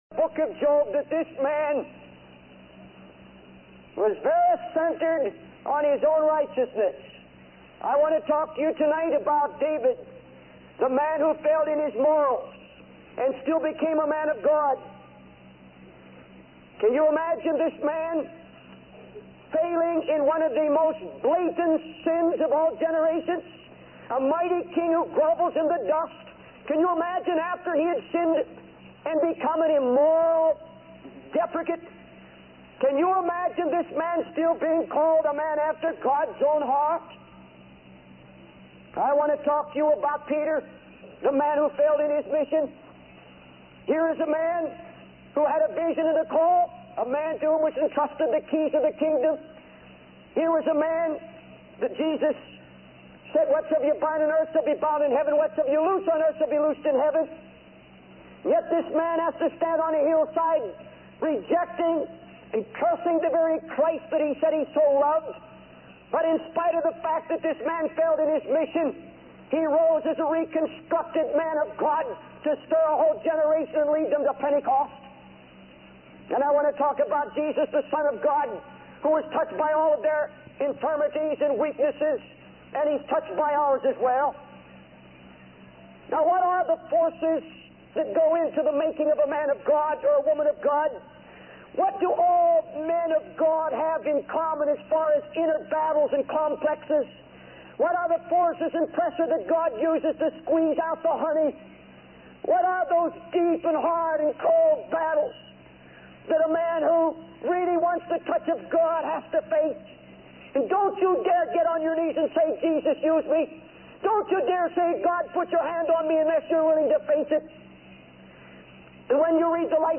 In this sermon, the preacher begins by expressing a desire to draw closer to God and be broken before Him. He emphasizes that even in the midst of trials and confusion, God is at work and will raise up a standard against the enemy.